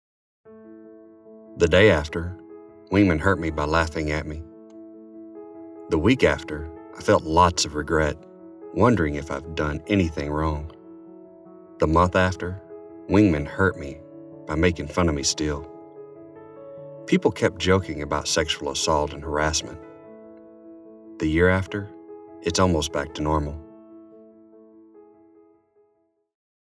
The secretary, chief of staff and chief master sgt. of the Air Force joined other Airmen to read testimonies from sexual assault survivors and discussed Airmen’s roles in supporting sexual assault survivors and preventing this crime.
30 Year Old Male Testimonial